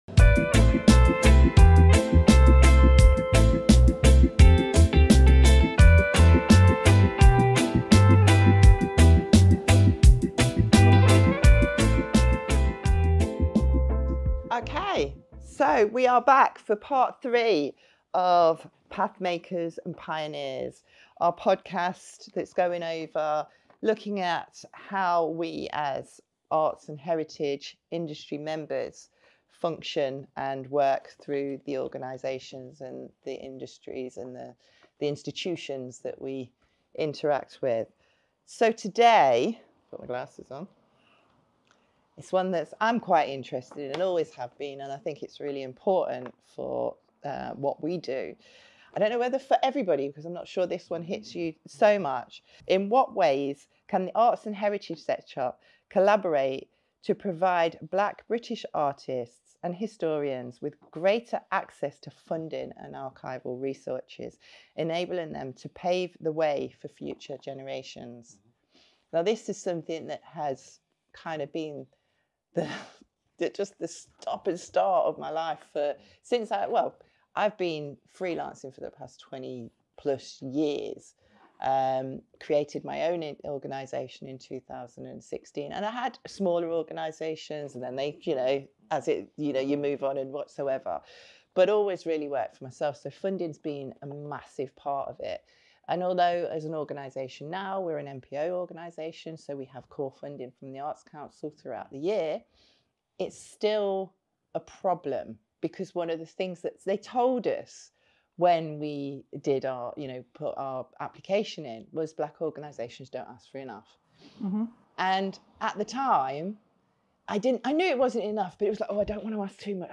in a thought-provoking discussion